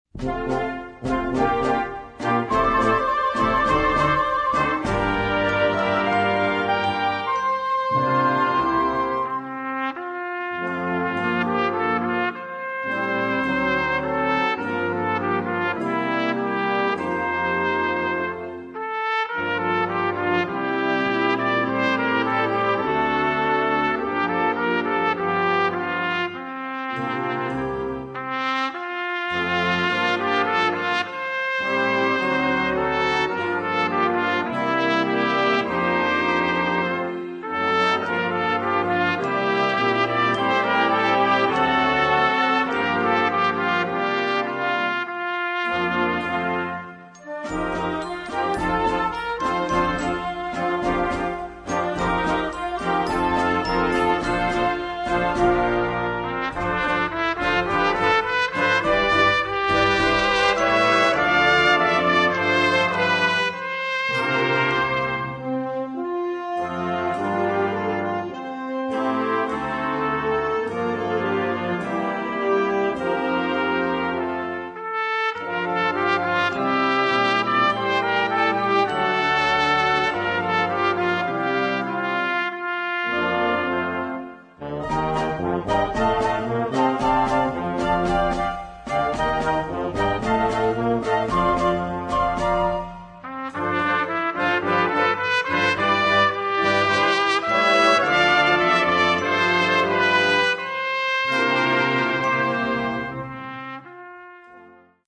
Partitions pour orchestre d'harmonie des jeunes.